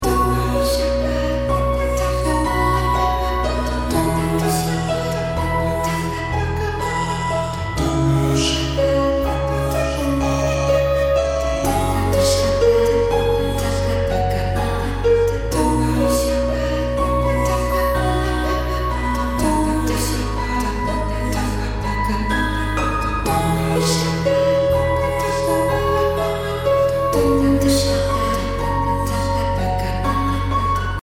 528hz calm Healing Instrument 癒し 穏やか
BPM 62